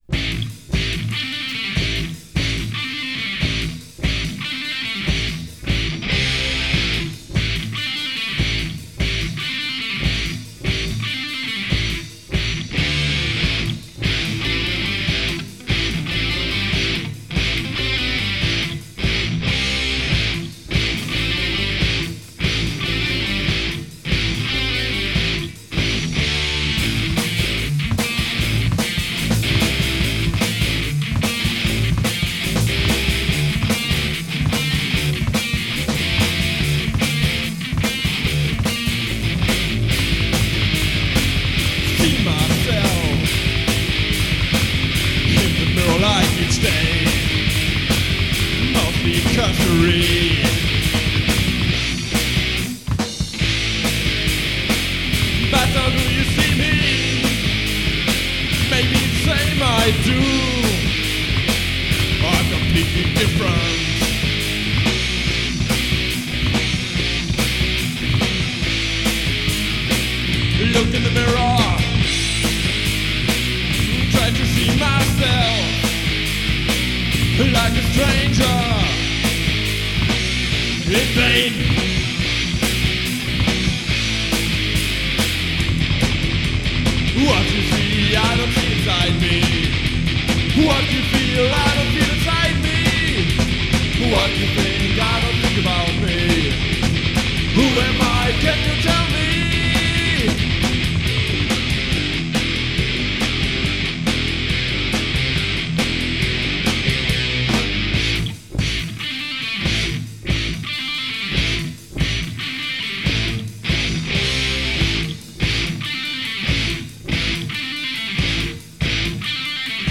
[Demo Tape 1994]
vocals, guitar, bass
drums